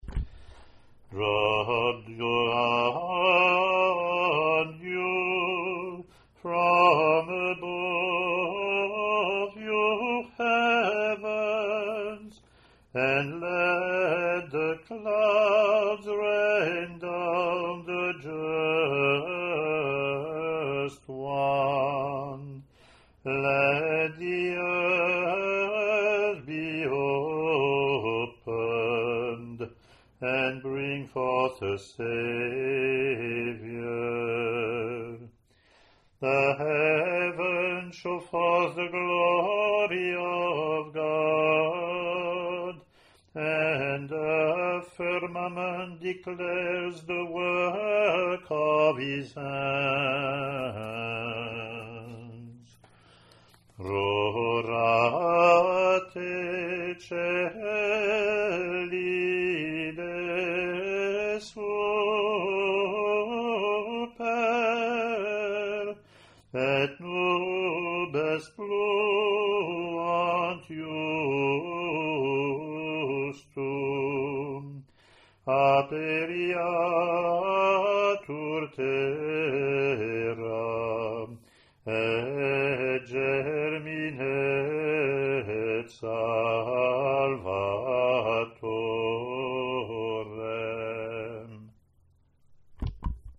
English antiphon – English verse – Latin antiphon and verse)